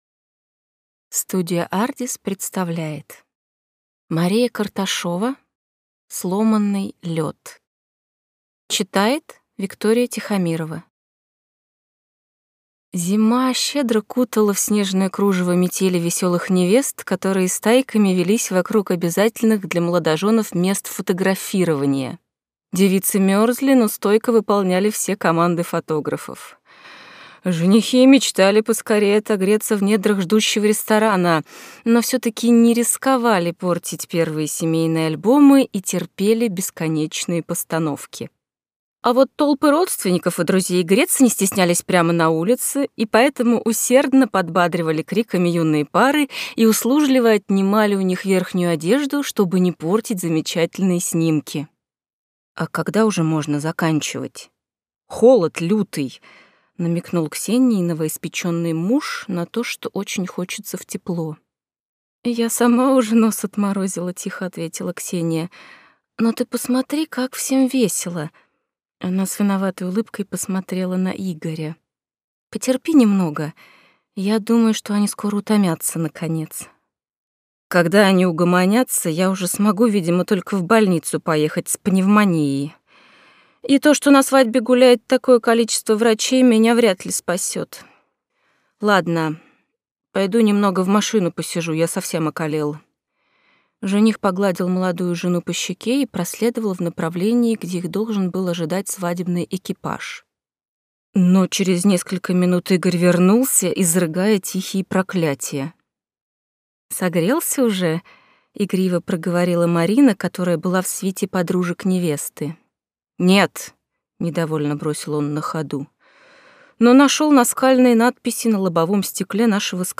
Аудиокнига Сломанный лёд | Библиотека аудиокниг